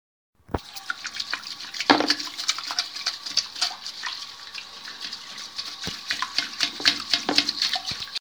Lavando un objeto con agua
Grabación sonora del sonido producido por alguien al lavar y frotar un objeto bajo un chorro de agua proveniente probablemente de un grifo
Sonidos: Agua
Sonidos: Acciones humanas